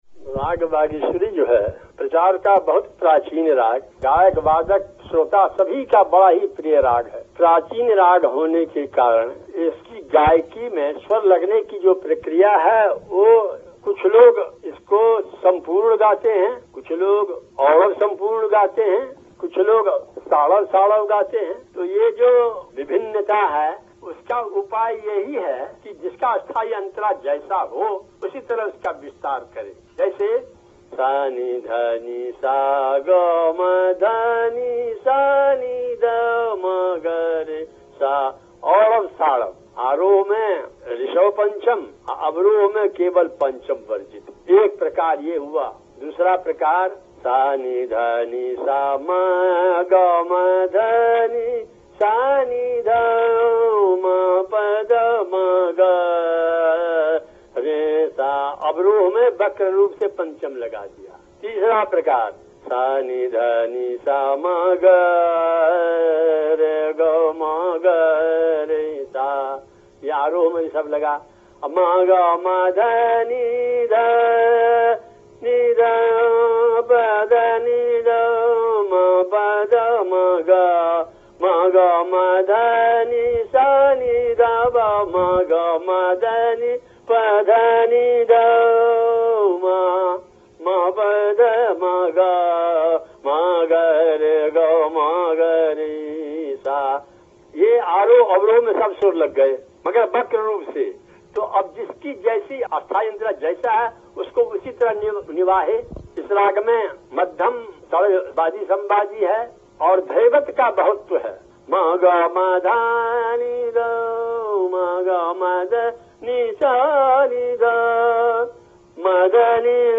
recorded over the California-Allahabad telephone link.